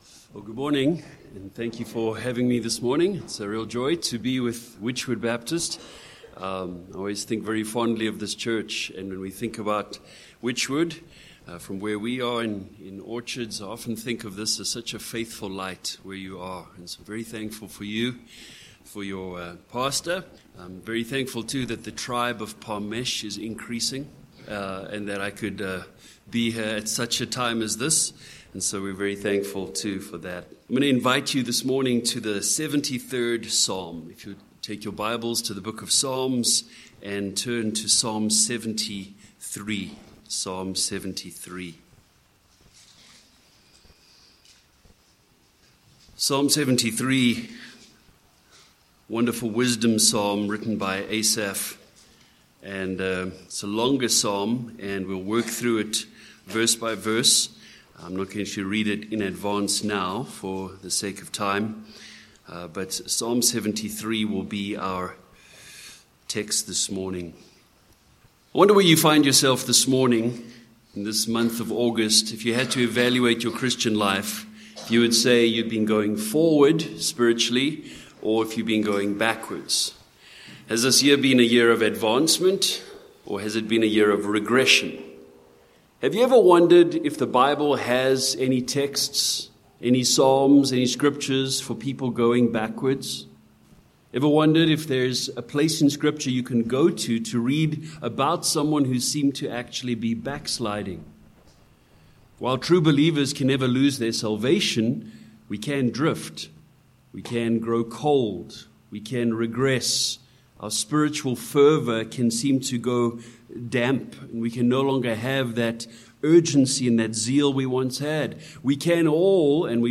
Sermon points: 1. Regression Through Envy v1-14